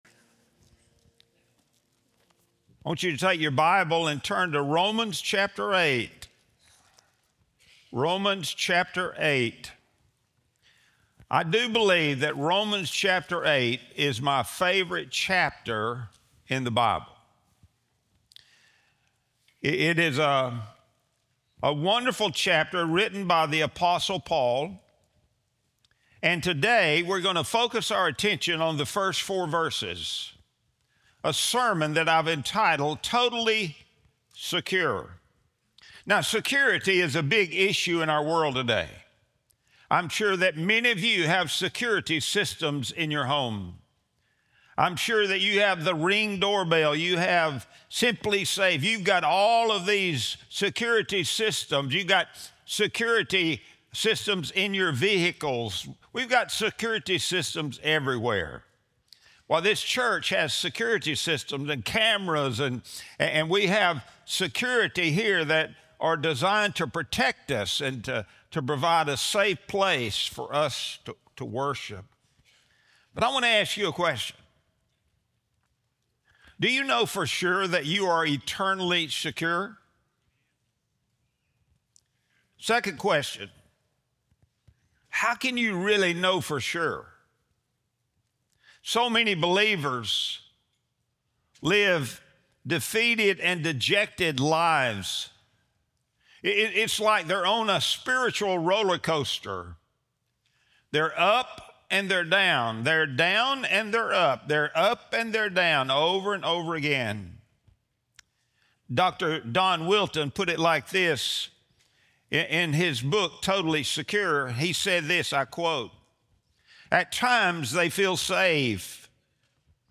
Sunday Sermon | February 8, 2026